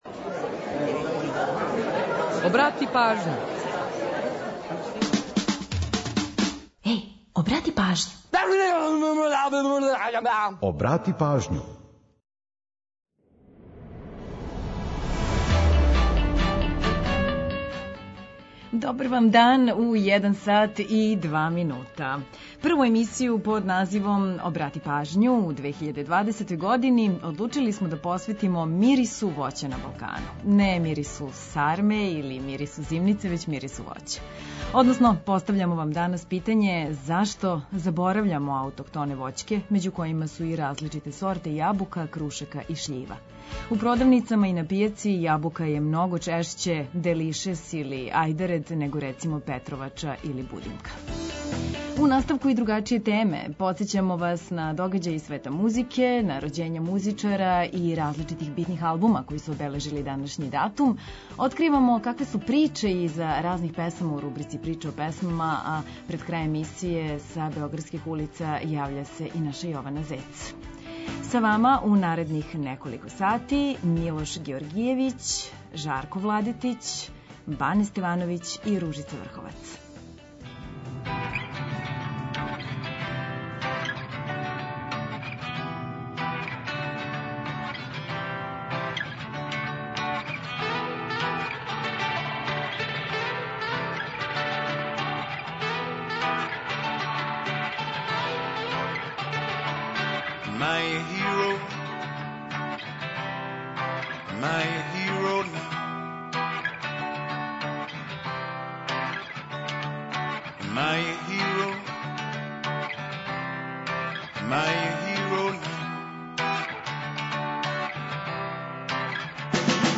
Подсећамо на догађаје из света музике, рођења музичара и албума, који су у вези са данашњим датумом, откривамо какве приче су иза разних песама, а ту је и пола сата музике из Србије и региона.